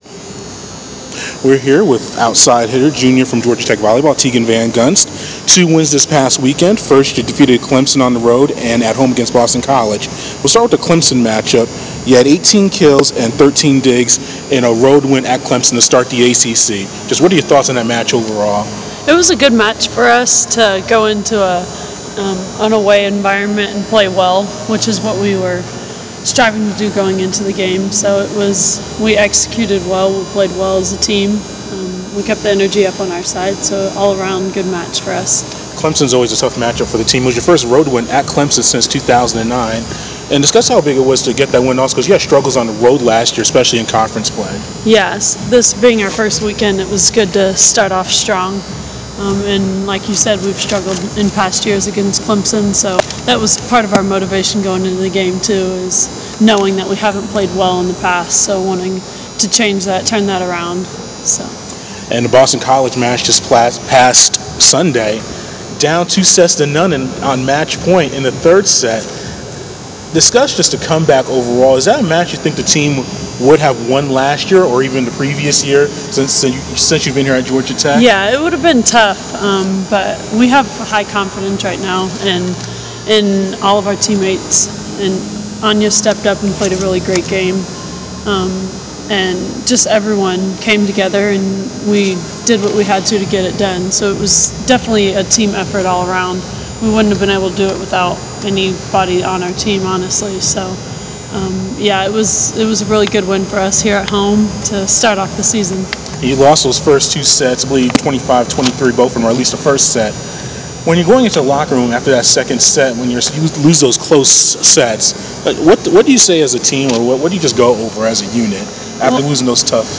Interview
before her team’s practice on Sept. 29